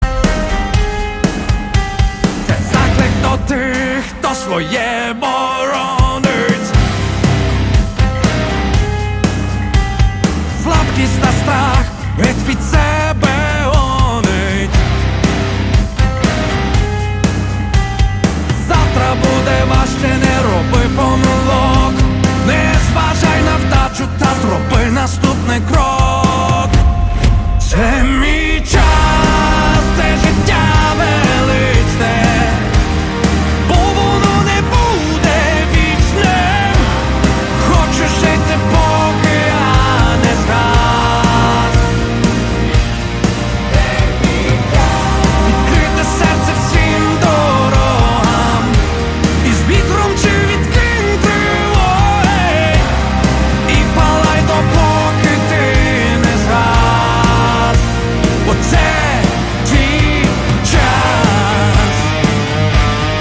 • Качество: 192, Stereo
громкие
Cover
Alternative Metal
arena rock
Кавер українською